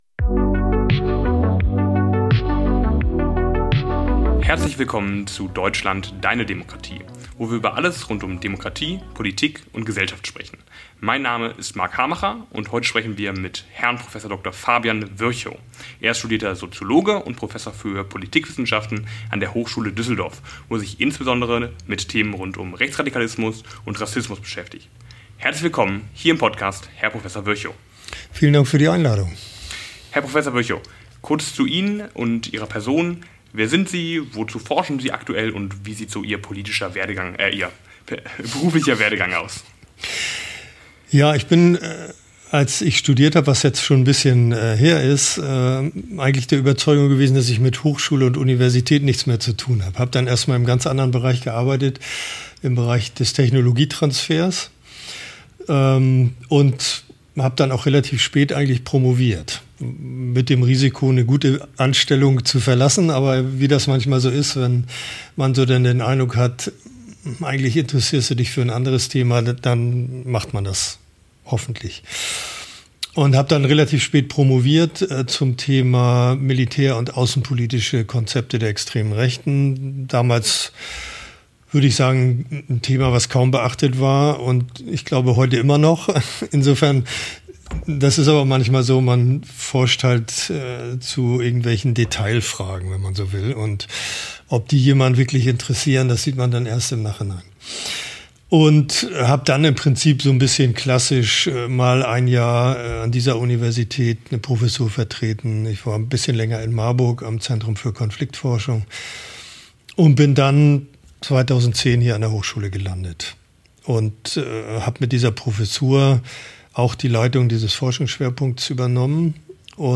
Ein Gespräch über die Hintergründe des Rechtsextremismus: Wie werden Menschen rechtsextrem? Wie konnte die AfD so weit kommen? Wie werden wir die AfD wieder los?